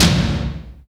GLOOM TOM.wav